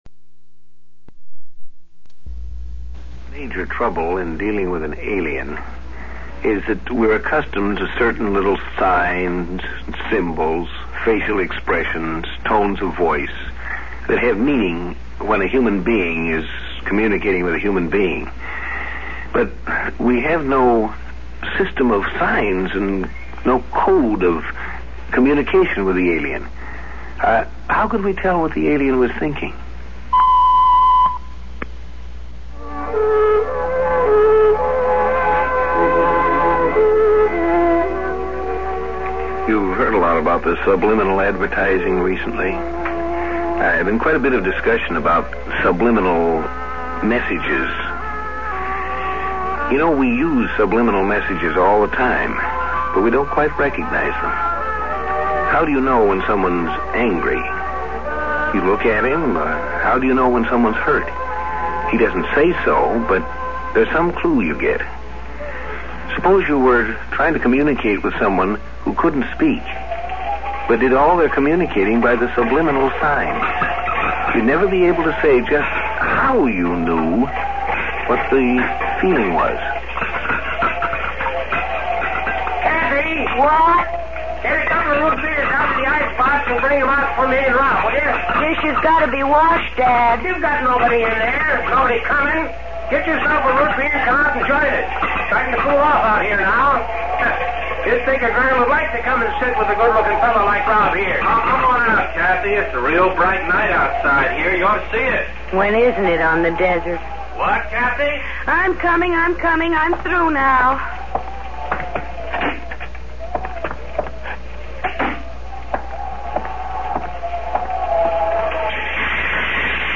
Exploring Tomorrow Science Fiction Radio Program